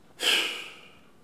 pfff-soulagement_02.wav